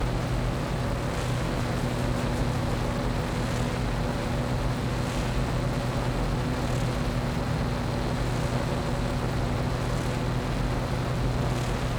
pgs/Assets/Audio/Sci-Fi Sounds/Mechanical/Engine 4 Loop.wav at master
Engine 4 Loop.wav